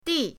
di4.mp3